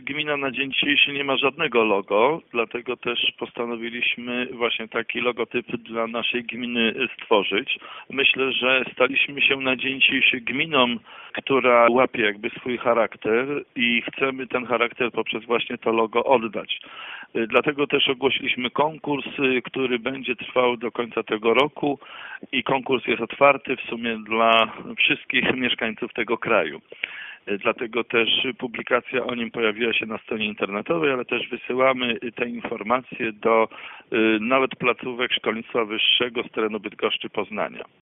Skąd wzięła się potrzeba stworzenia logotypu wyjaśnia burmistrz Jacek Idzi Kaczmarek.